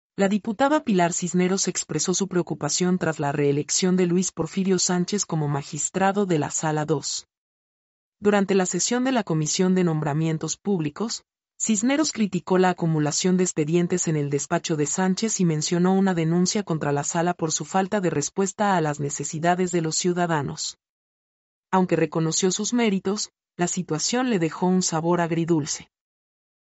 mp3-output-ttsfreedotcom-65-1.mp3